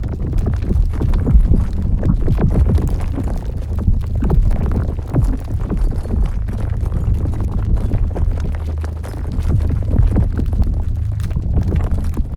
gravi_idle01.ogg.bak